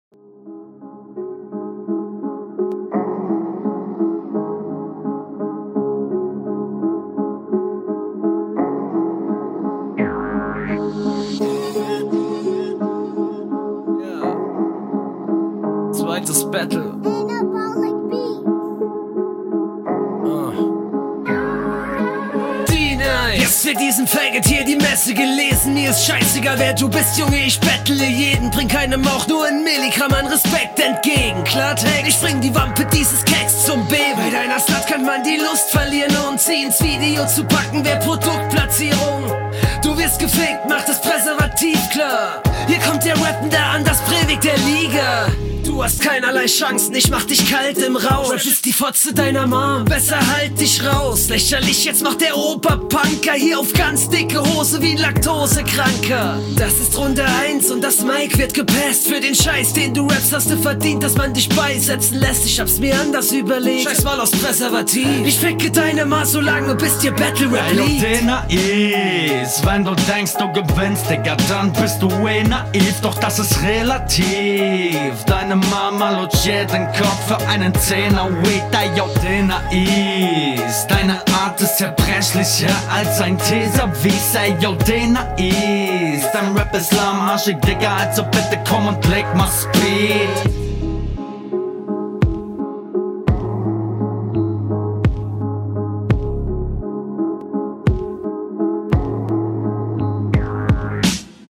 Flow: Flowlich eigentlich alles safe. Nichts Ausgefallenes, variierst nicht groß, aber bist eigentlich immer gut …